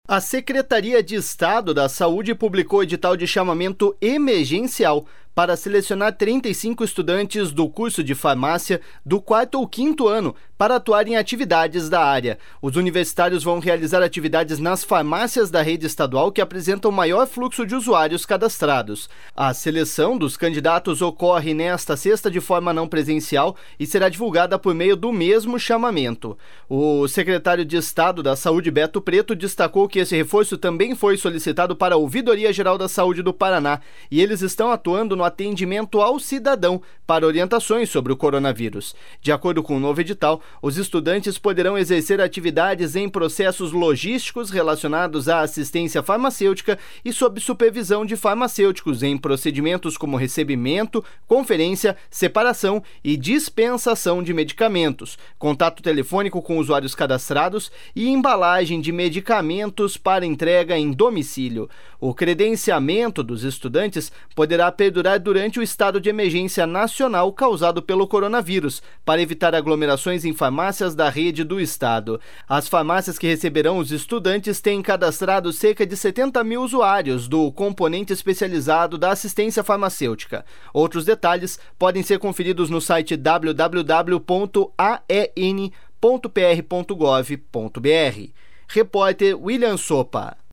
O secretário de Estado da Saúde, Beto Preto, destacou que este reforço também foi solicitado para a Ouvidoria-Geral da Saúde do Paraná, e eles estão atuando no atendimento ao cidadão para as orientações sobre o coronavírus.